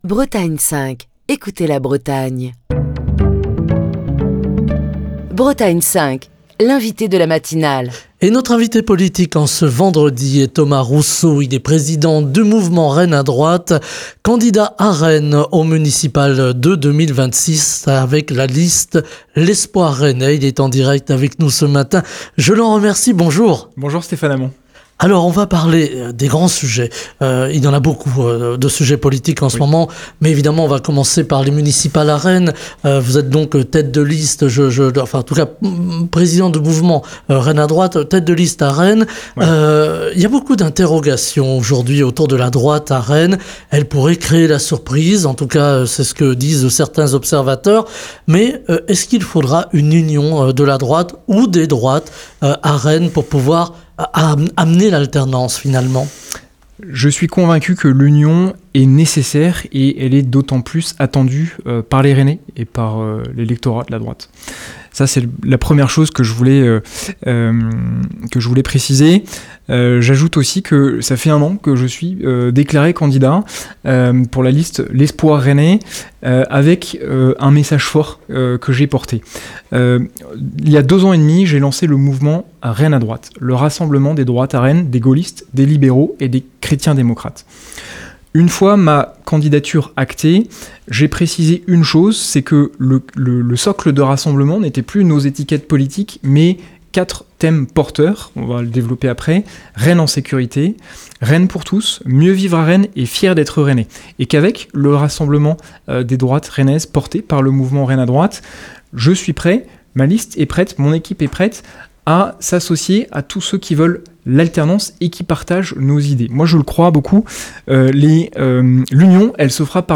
était l'invité politique de la matinale de Bretagne 5.